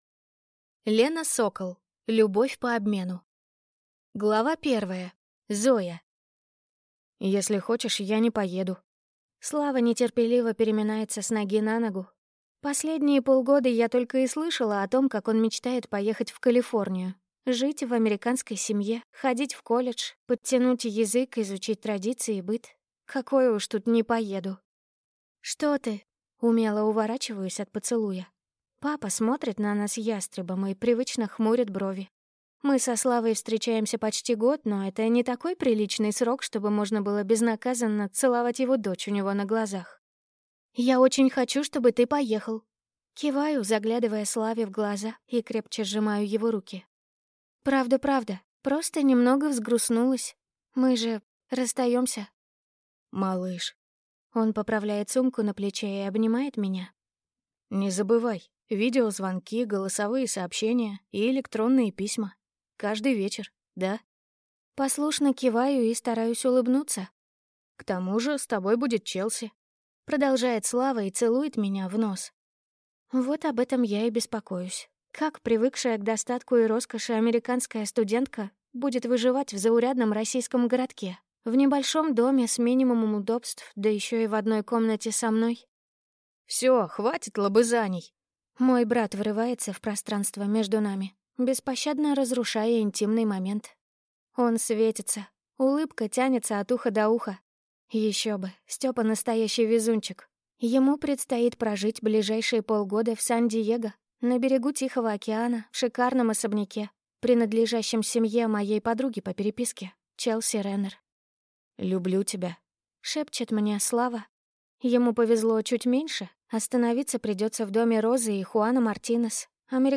Аудиокнига Любовь по обмену | Библиотека аудиокниг
Прослушать и бесплатно скачать фрагмент аудиокниги